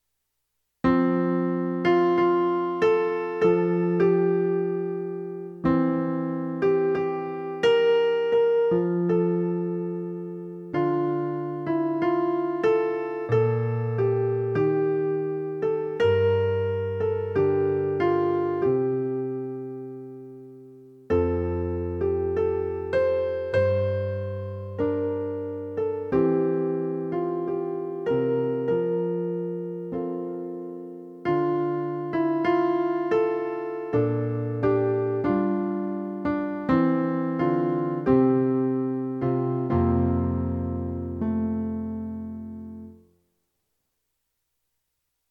AsIHaveDoneForYou_Soprano.mp3